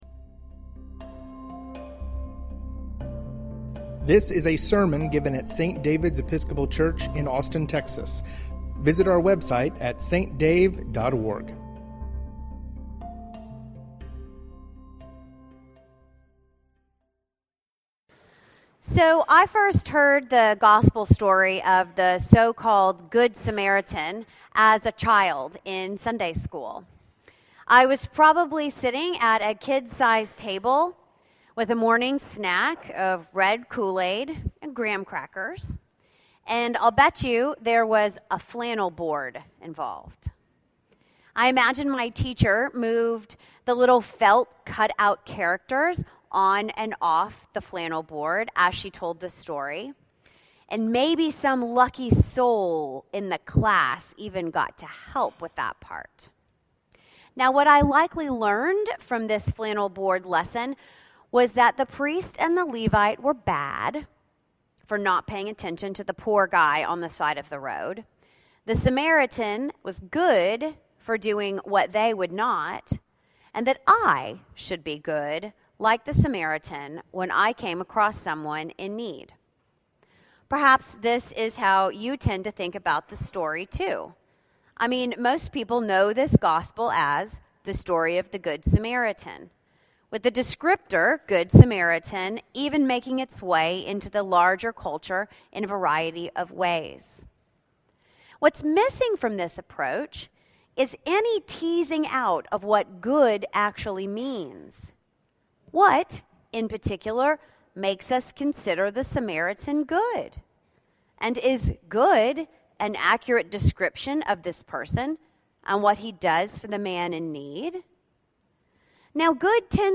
The Abbey at St. David's Sermon